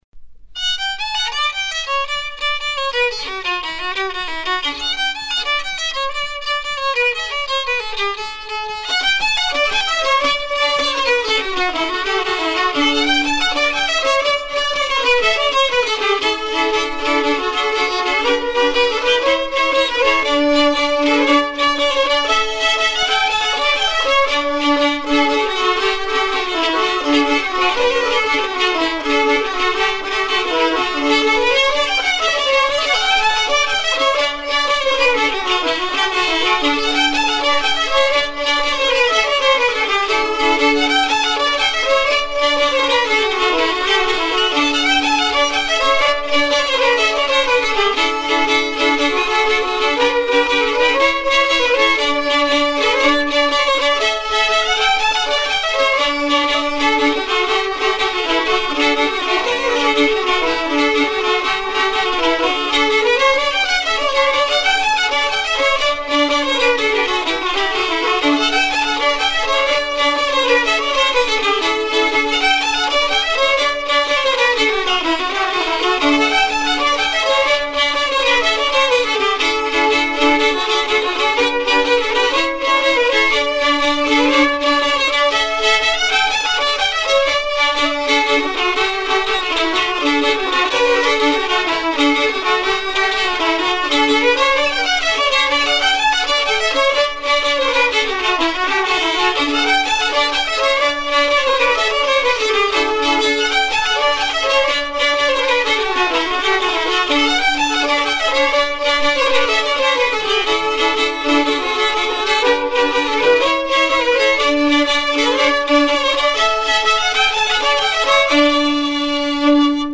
danse : matelote
Pièce musicale éditée